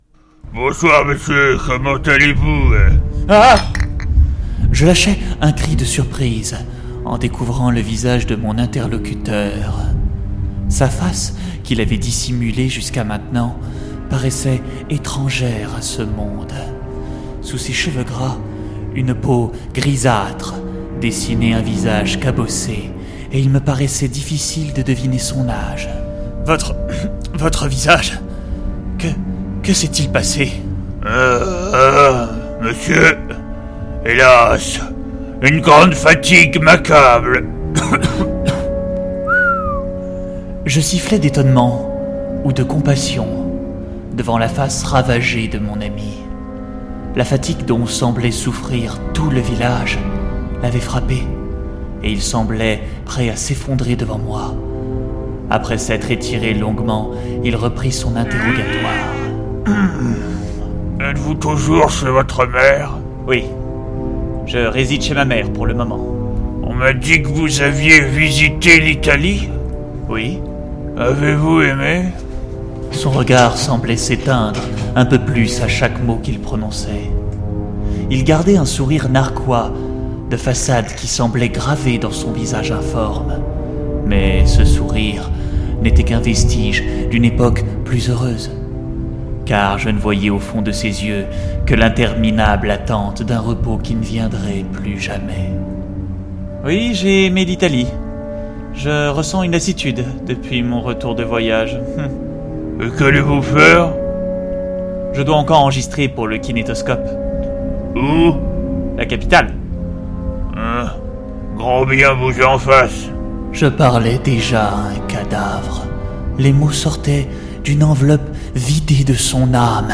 version lugubre tête de ouf.mp3
version_lugubre_tête_de_ouf.mp3